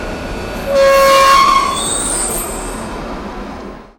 Subway Arrival
A subway train arriving at a platform with braking squeal, door chime, and air release
subway-arrival.mp3